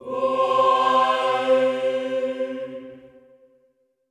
Index of /90_sSampleCDs/Best Service - Extended Classical Choir/Partition D/HOO-AHH-EHH
HOO-AH  C3-L.wav